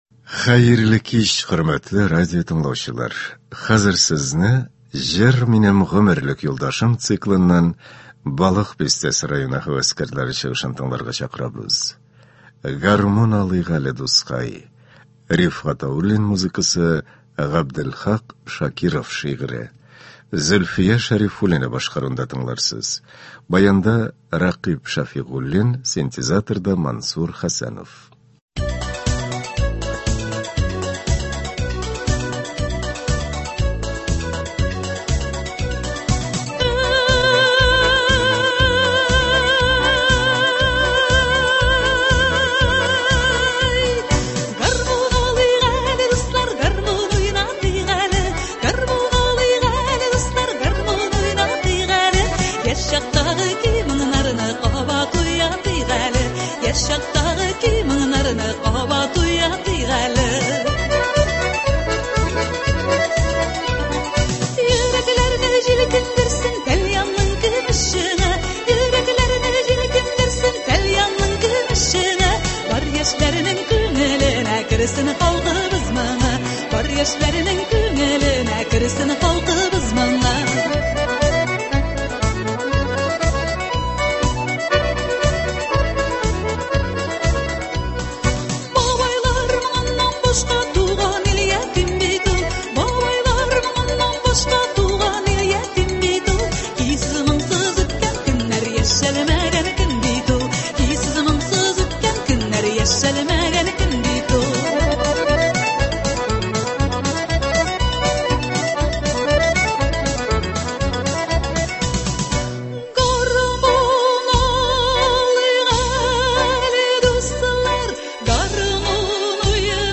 Концерт (23.05.22)